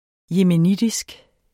yemenitisk adjektiv Bøjning -, -e Udtale [ jeməˈnidisg ] Betydninger fra Yemen; vedr.